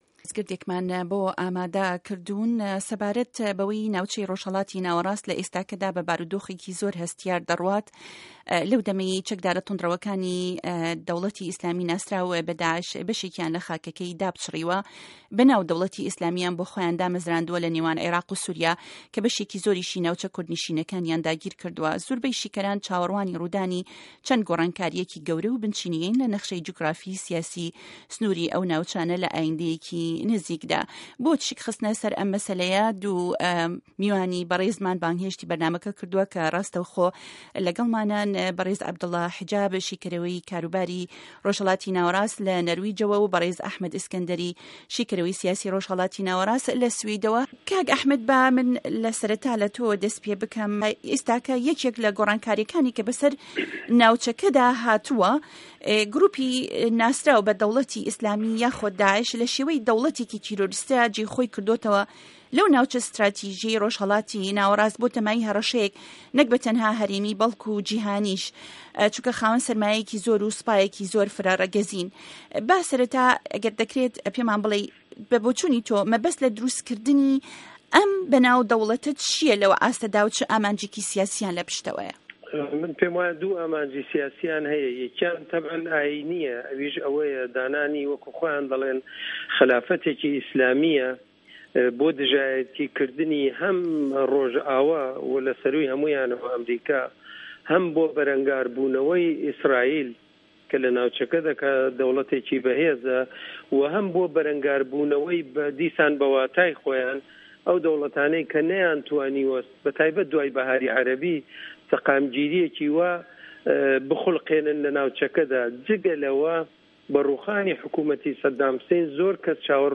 مێزگرد: شیانی گۆرانکاری له‌ نه‌خشه‌ی ڕۆژهه‌ڵاتی ناوه‌ڕاست له‌ ئاینده‌یه‌کی نزیکدا